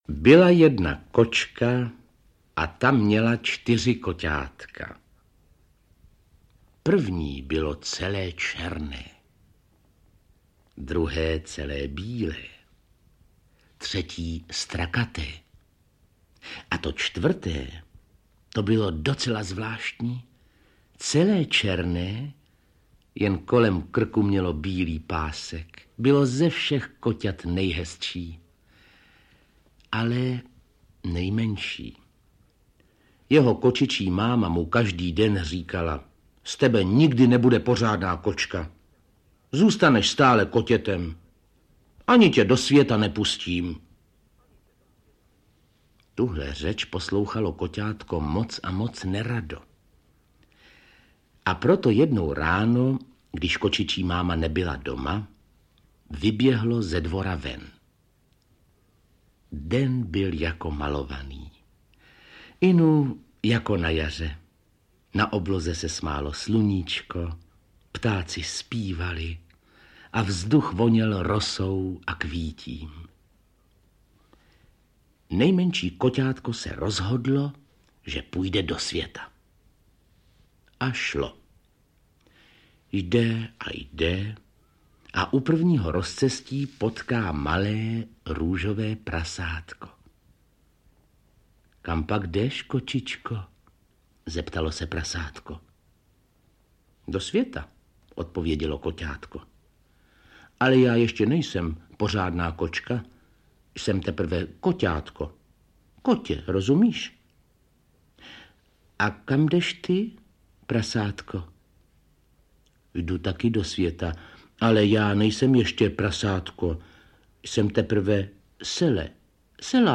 Audio knihaAlbum pohádek
Ukázka z knihy